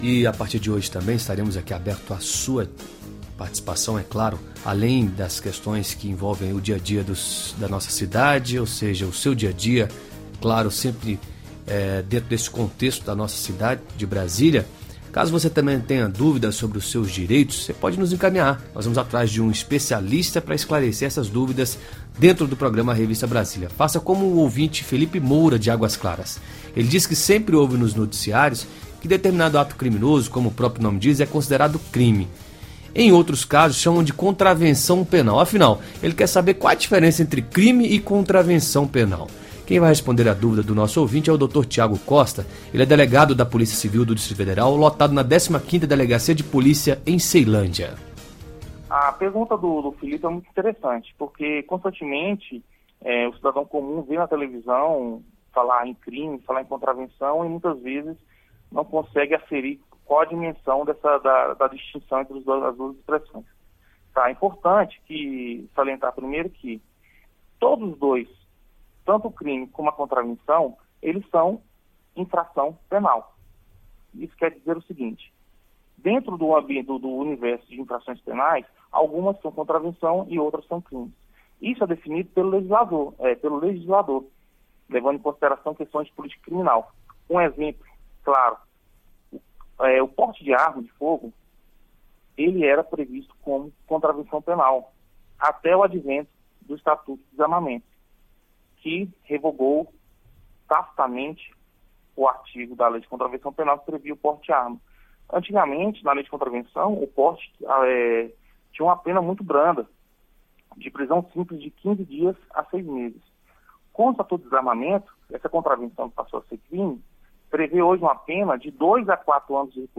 falou sobre o assunto durante entrevista no programa Revista Brasília, da Rádio Nacional de Brasília – AM 980.